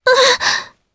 Laias-Vox_Damage_jp_01.wav